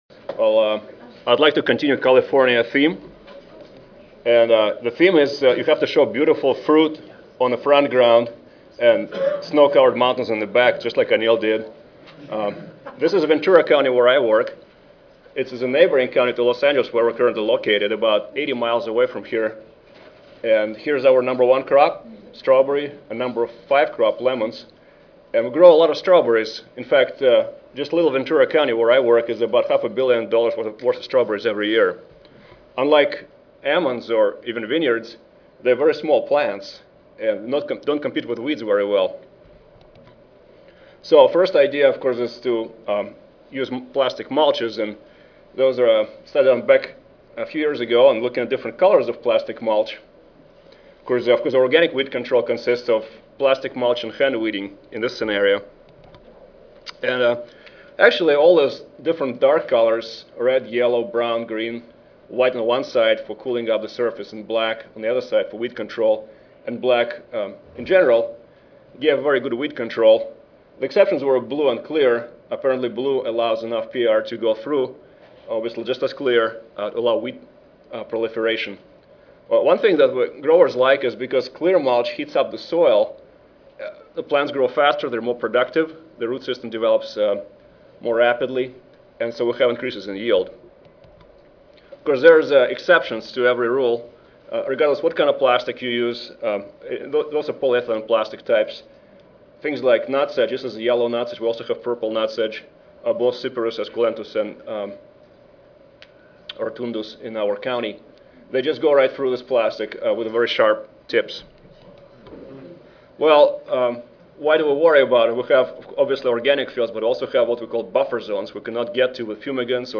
Davis Audio File Recorded presentation